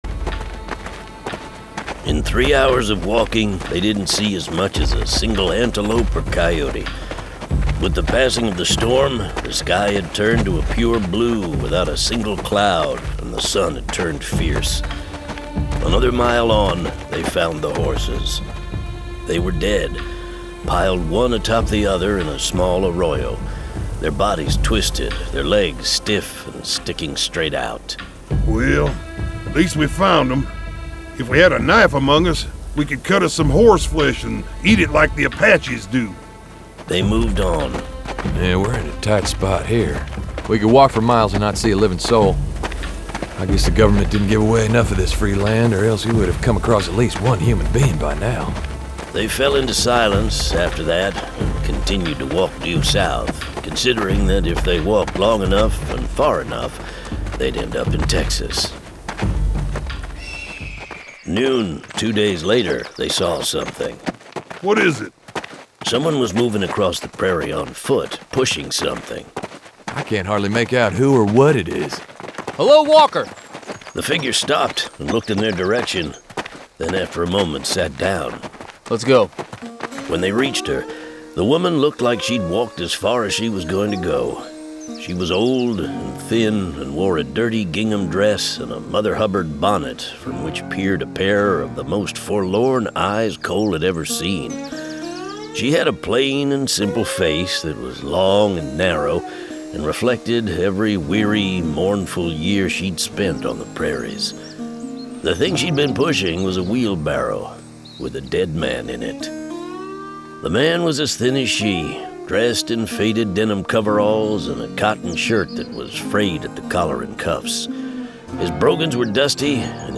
John Henry Cole 3: Winter Kill [Dramatized Adaptation]